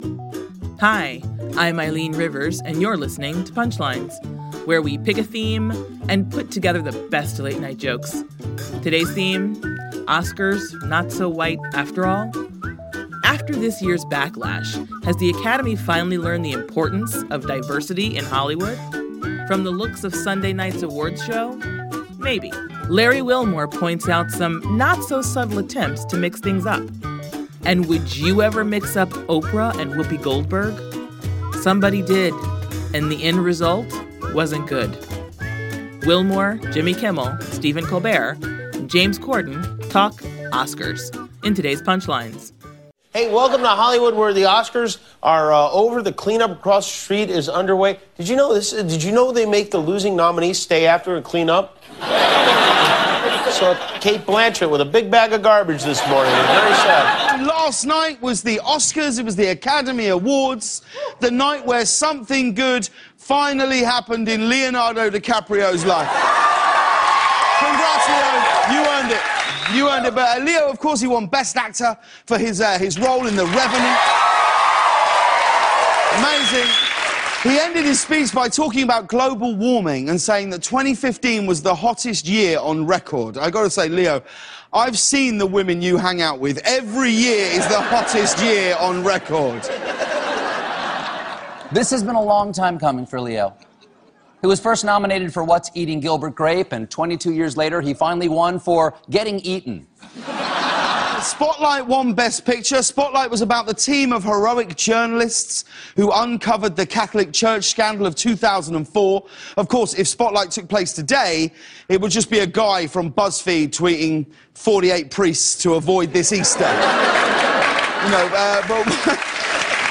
The late-night comics on the recent Academy Awards, including attempts at diversity.